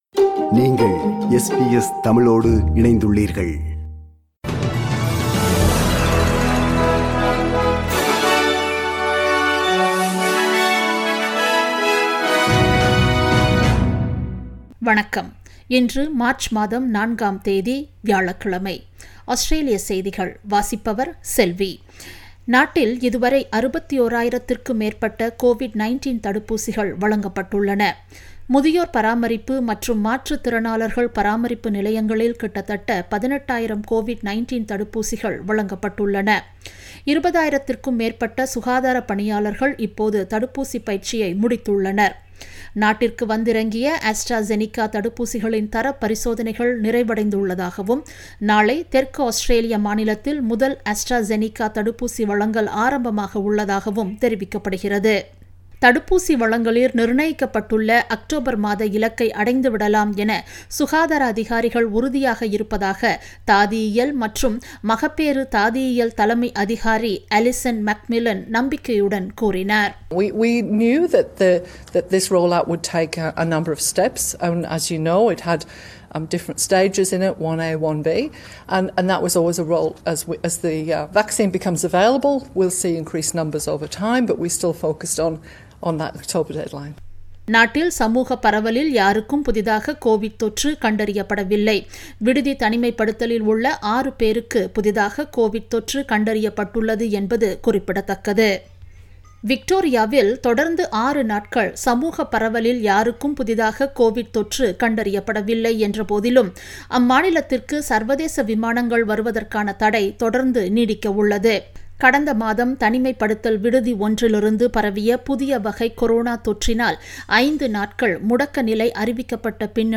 Australian news bulletin for Thursday 04 March 2021.